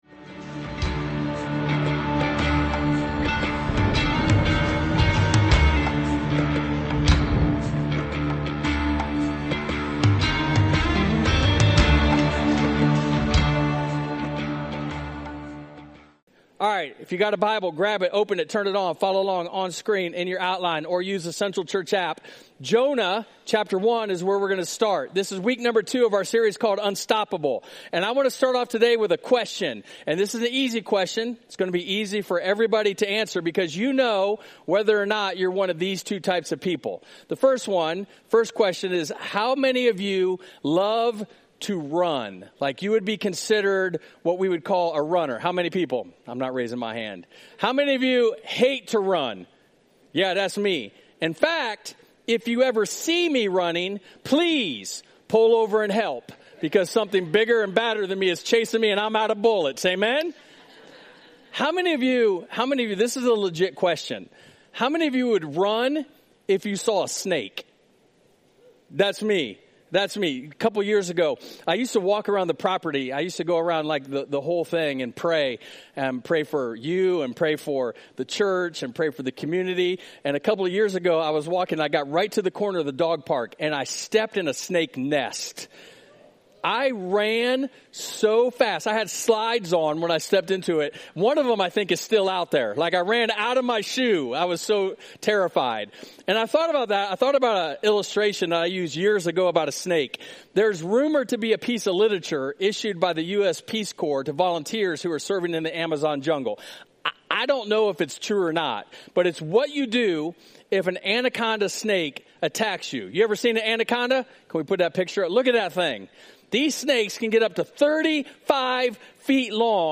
We're in week 2 of our new sermon series, Unstoppable, with "You Can't Out Run God" - inspired by the account of Jonah.